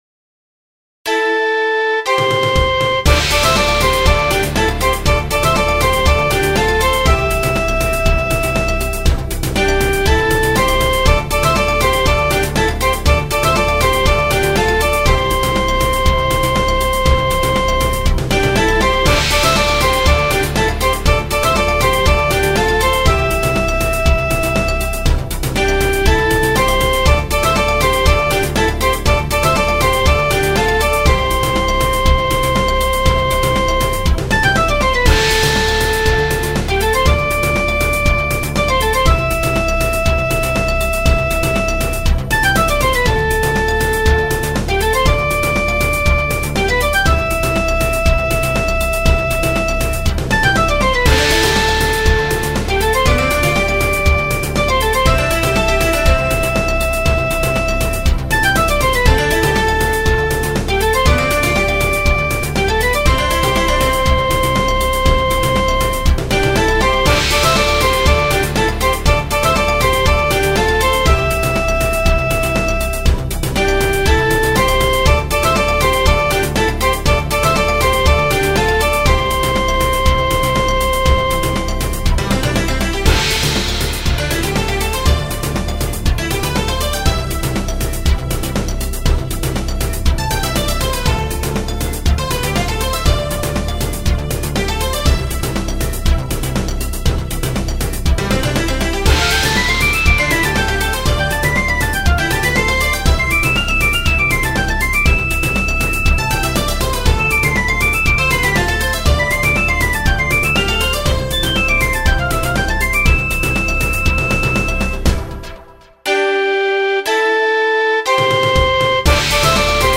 エレクトロニカロング明るい
BGM